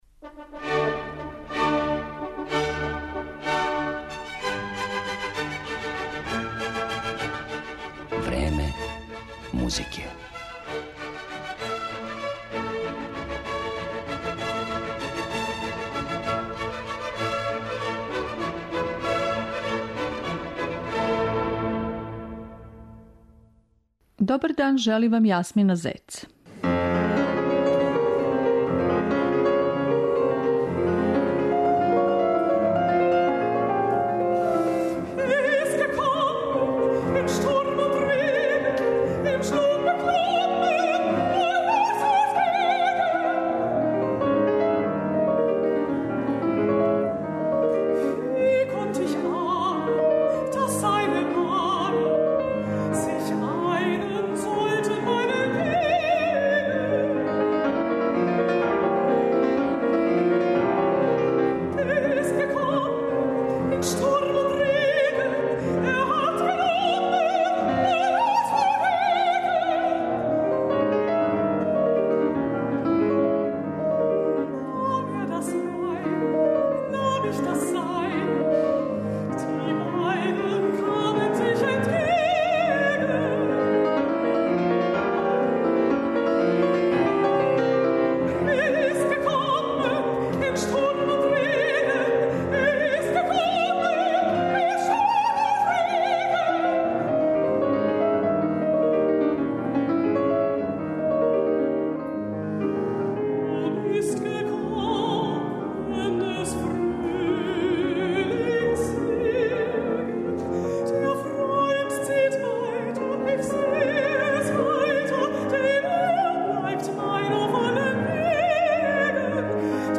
Јулијана Банзе ужива репутацију једне од најбољих интерпретаторки немачког лида. До 14 часова и 55 минута емитоваћемо соло песме Јоханеса Брамсa и Кларе и Роберта Шумана.